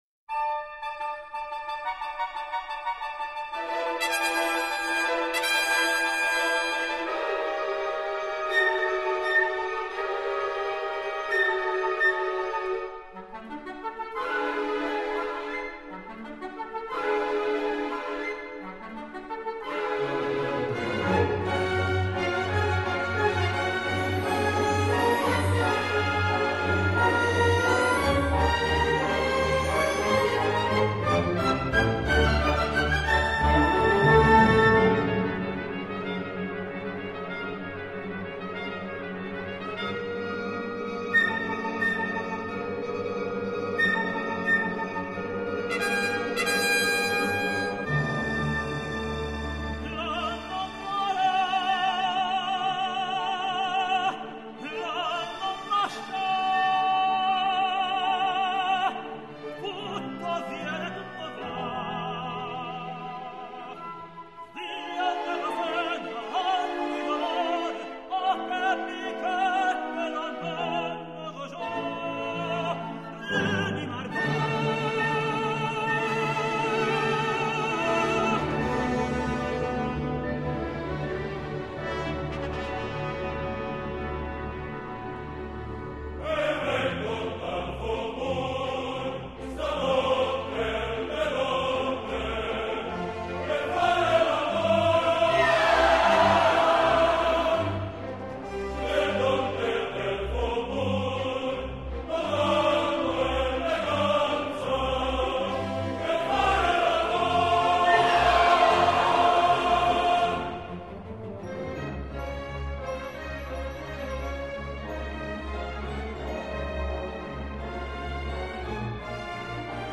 voce di tenore & coro
Eine Tenorstimme